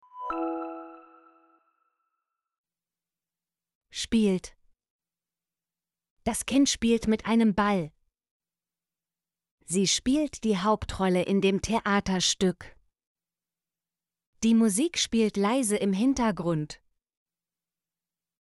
spielt - Example Sentences & Pronunciation, German Frequency List